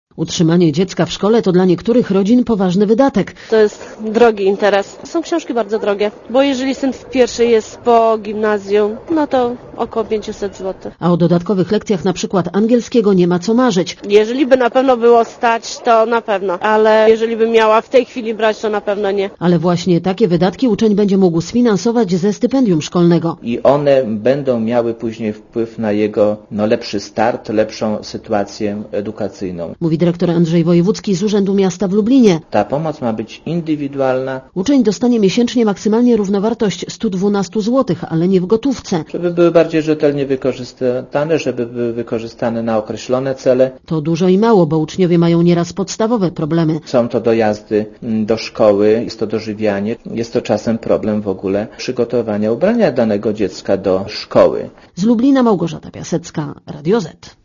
Relacja reportera Radia ZET O stypendia i zasiłki szkolne będą mogli starać się uczniowie, u których w rodzinach miesięczny dochód na osobę nie przekracza 316 złotych.